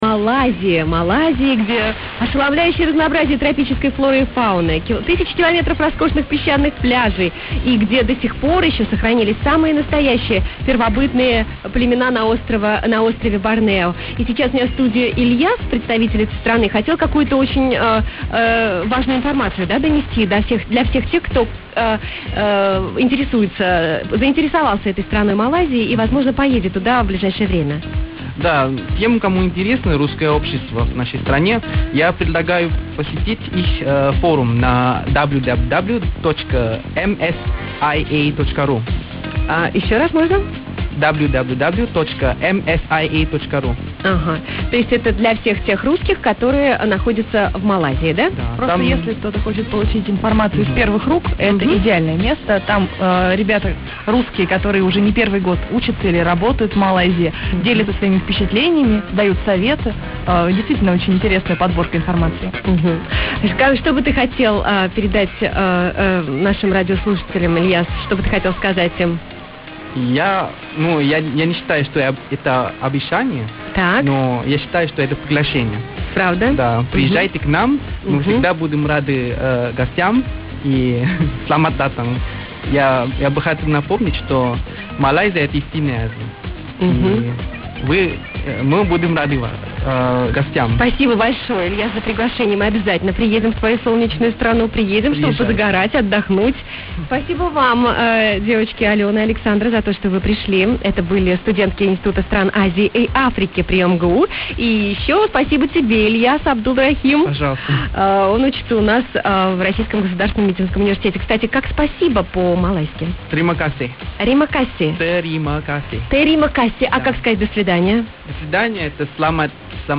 Ведущая не очень понравилась, говорила без остановки, приглашенным почти и сказать ничего не дала.